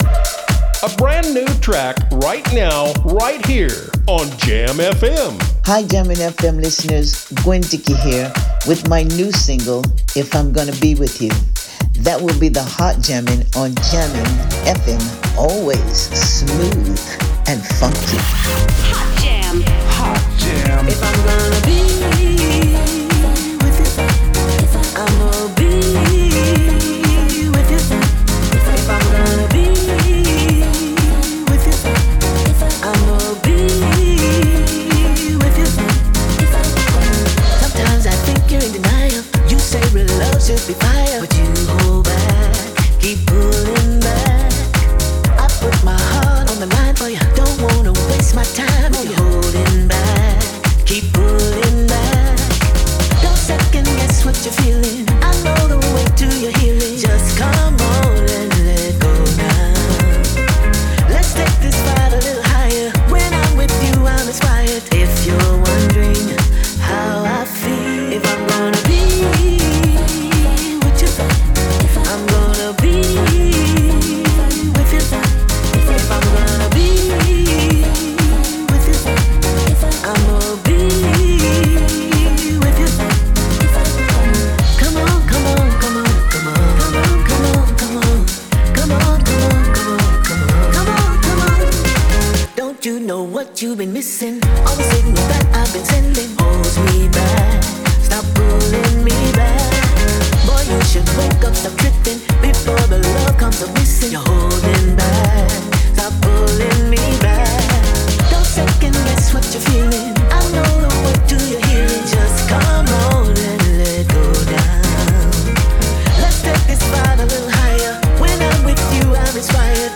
is een gerenommeerde Amerikaanse soul- en R&B-zangeres.
soulvolle stem
moderne R&B-productie